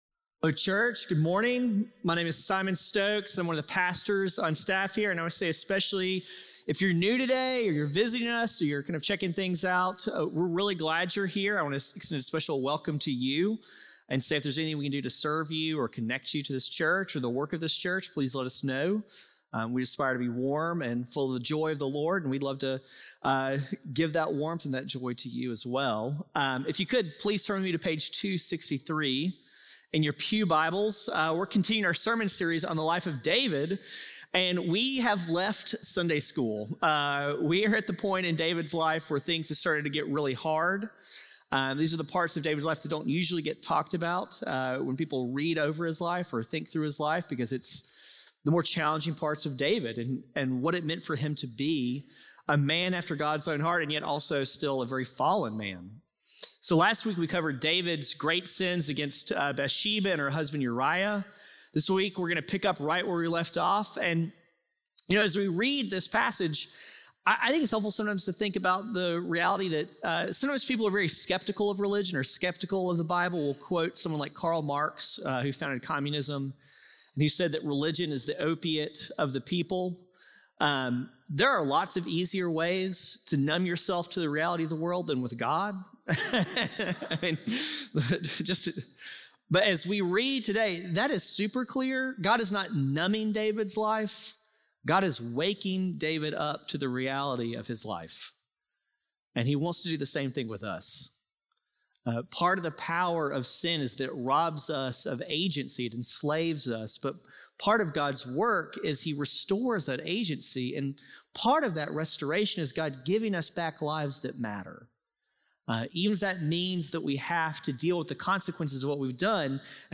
Text: 2 Samuel 12:1-15 View this week’s bulletin . View this week’s sermon discussion questions .